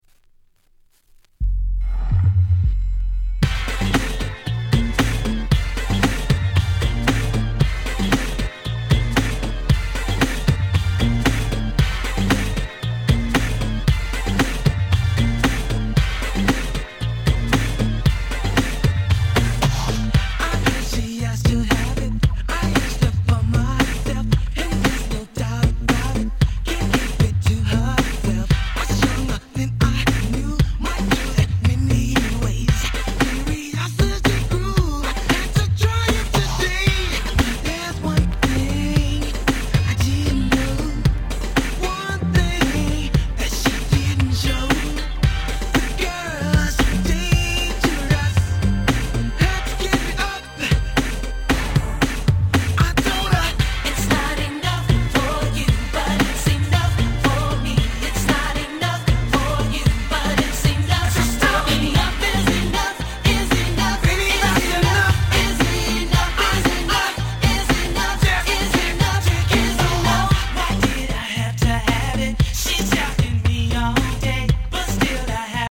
92' 人気New Jack Swing！！